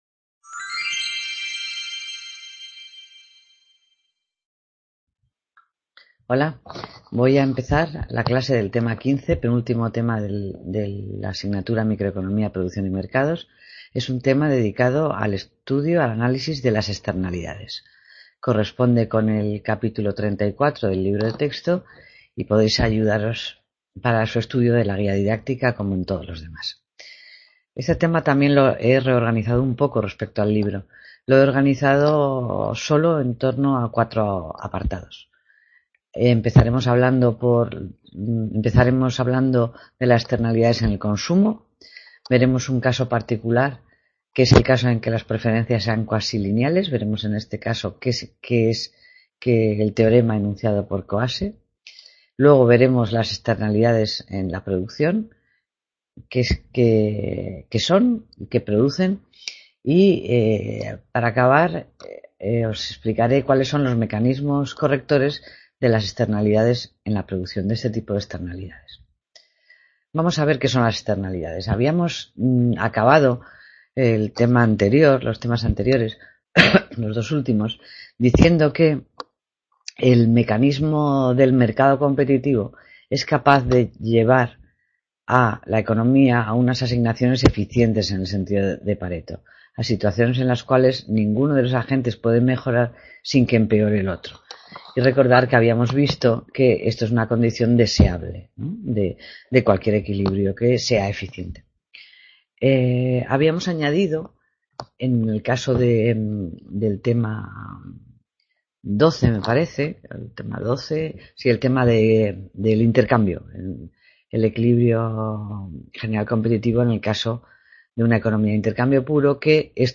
Clase Tema 15: Las externalidades | Repositorio Digital